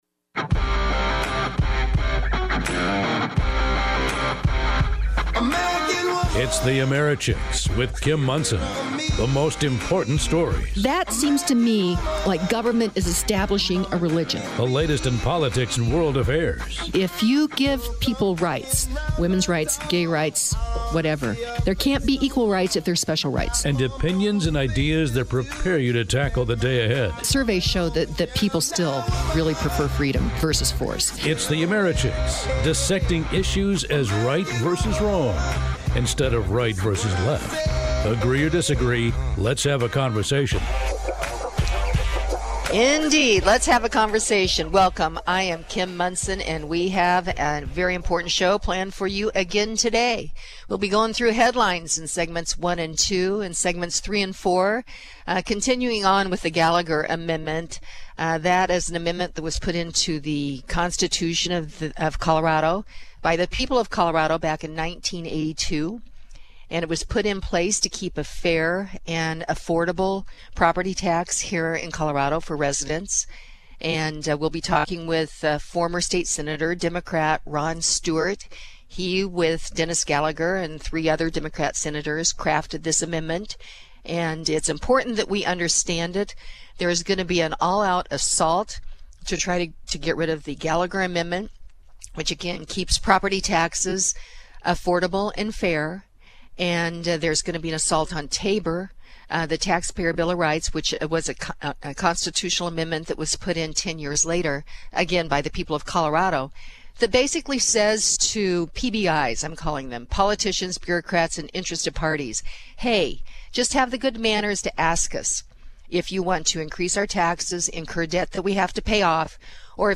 A Conversation with the Architect of the Gallagher Amendment